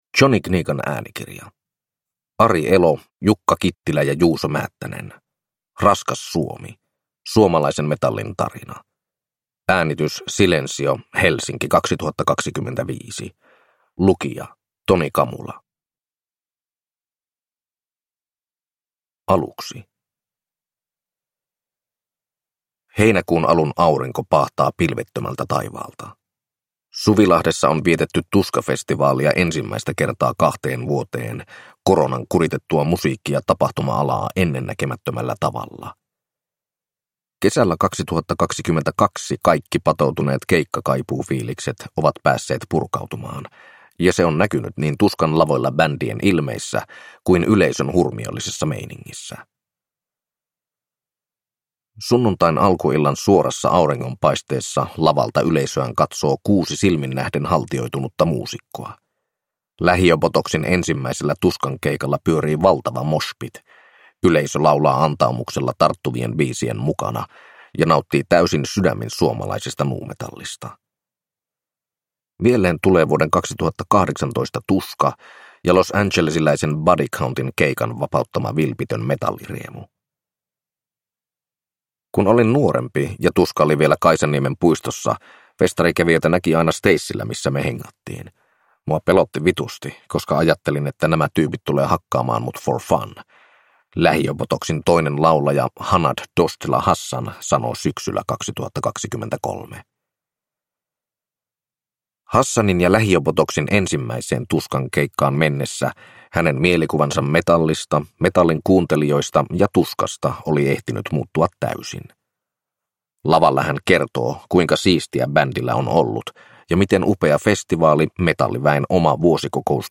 Raskas Suomi – Ljudbok